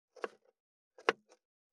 515切る,包丁,厨房,台所,野菜切る,咀嚼音,ナイフ,調理音,まな板の上,料理,
効果音厨房/台所/レストラン/kitchen食器食材